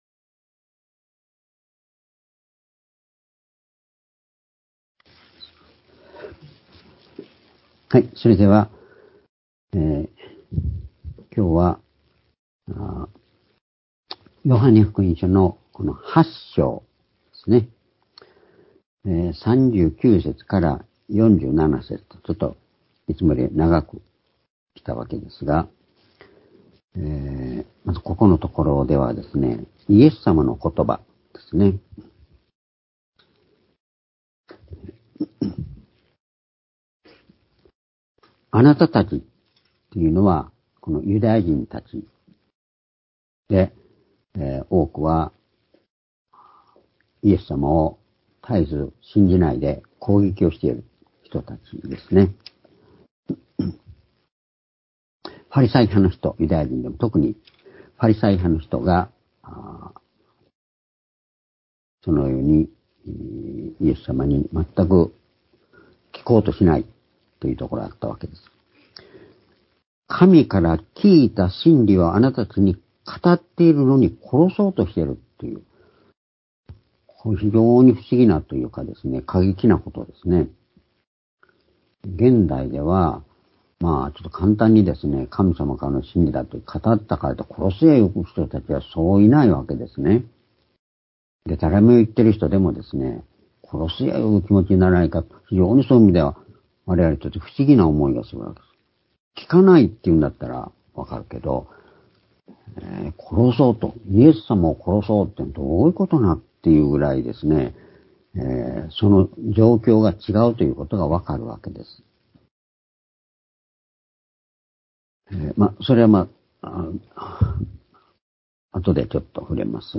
「神の真理を語り、聞くこと」-ヨハネ８章３９節～４７節--２０２３年３月１２日（主日礼拝）